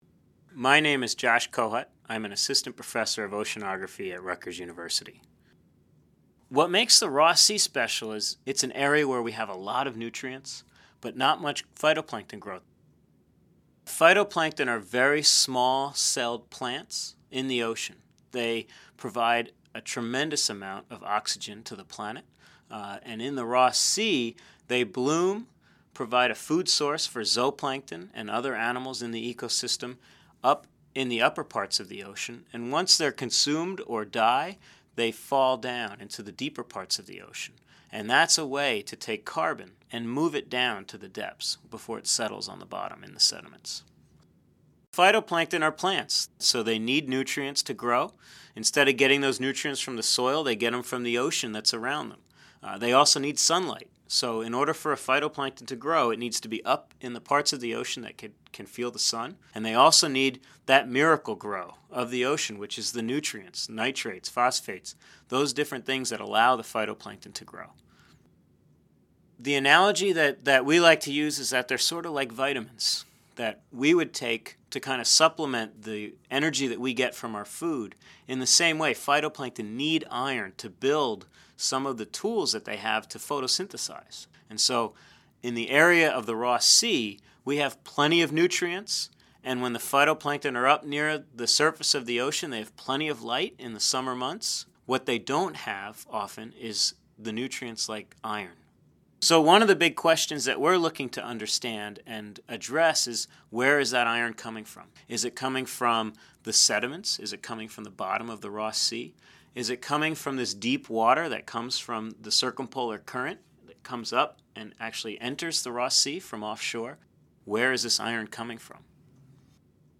An interview with physical oceanographer